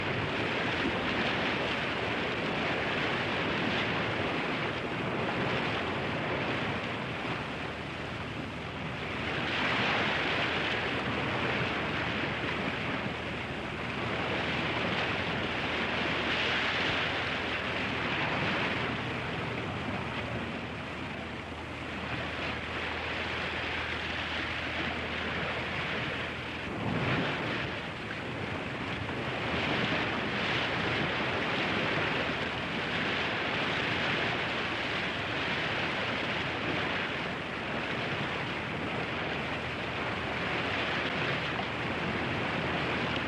взбалтывание винтов подводных лодок прибой или бурлящая вода постоянный
vzbaltivanie_vintov_podvodnih_lodok__priboj_ili_burlyashaya_voda__postoyannij_nkq.mp3